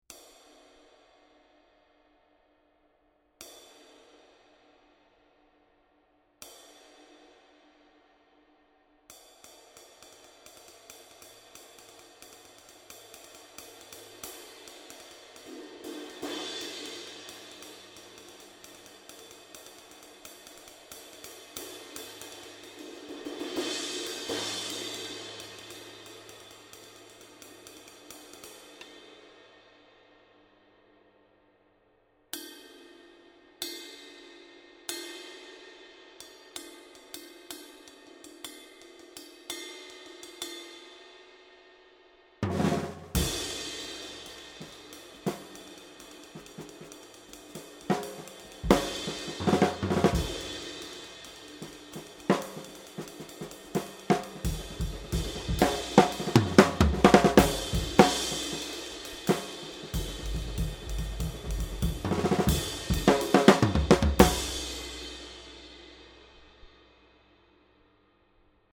22" Master Vintage Ride (Ex-dry) 2396g